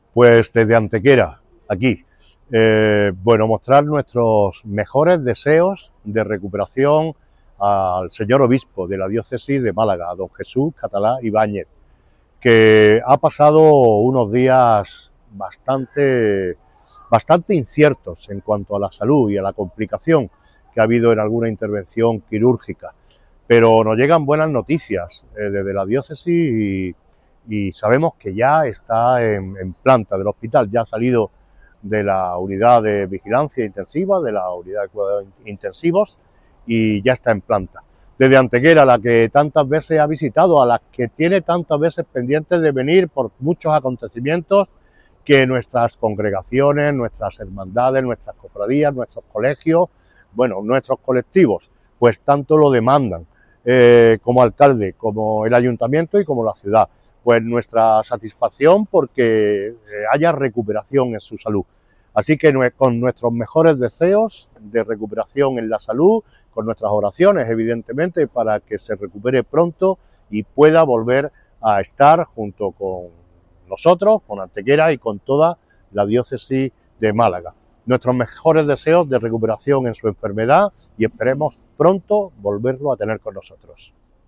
El alcalde de Antequera, Manolo Barón, ha enviado a través de un vídeo un mensaje de ánimo y cercanía al Obispo de Málaga, monseñor Jesús Catalá, con motivo de los recientes problemas de salud que ha atravesado debido a complicaciones derivadas de varias intervenciones quirúrgicas.
Cortes de voz